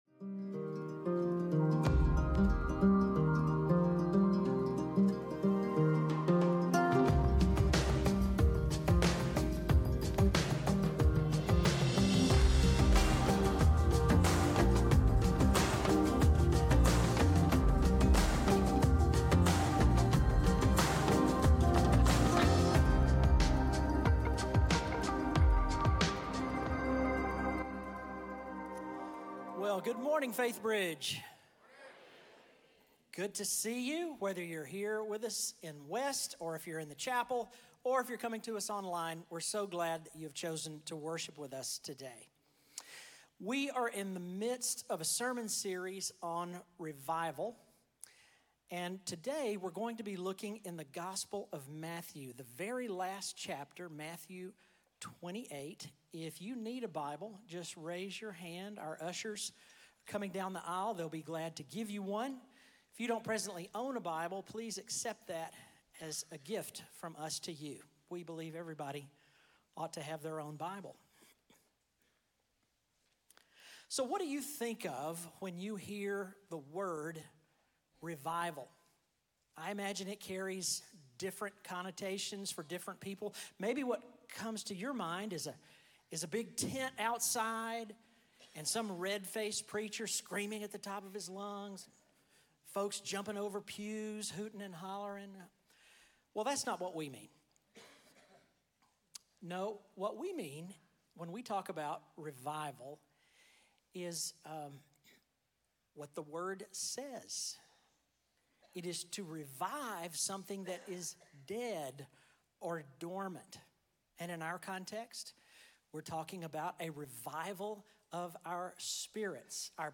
Weekly biblically-based sermons from Faithbridge church in Spring, Texas.